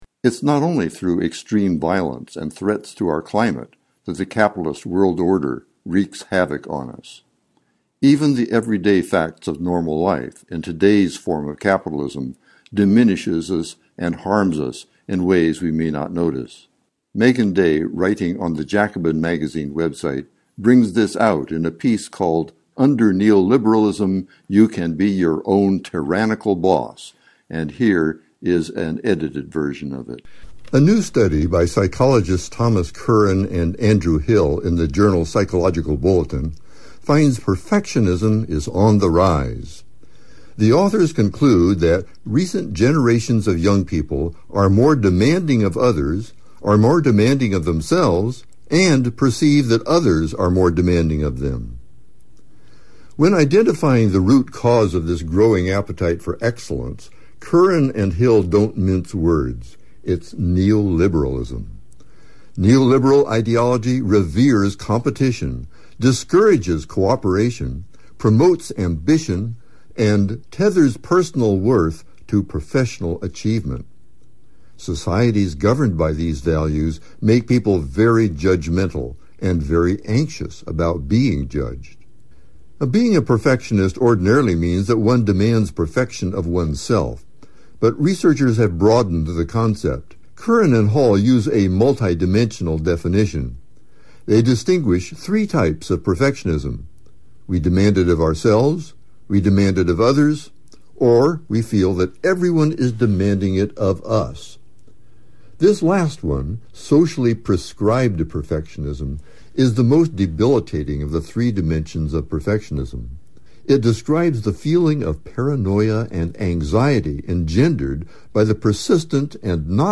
reads an edited version of her article, which is posted on the Jacobin Magazine website.